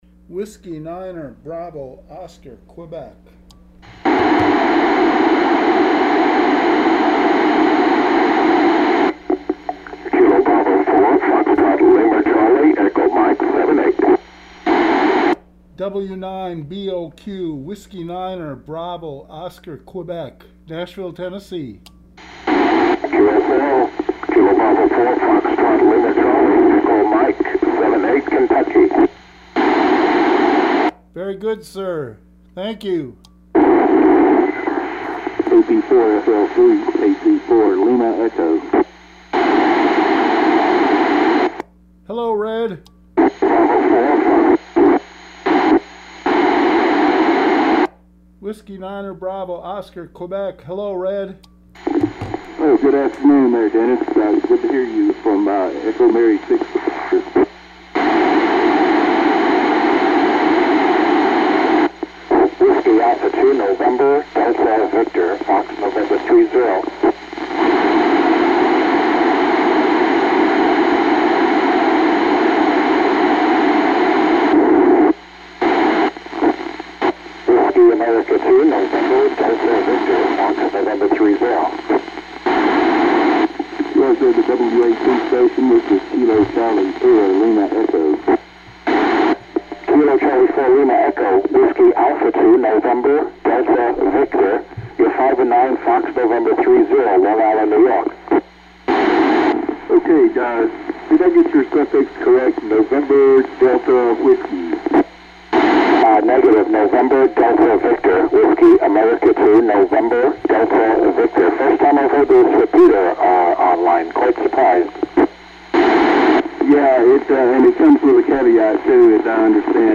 ISS Repeater Contact Recording.
Mode: REPEATER
Downlink: 145.800
Great signal from EM78EE.
Edited out dead spots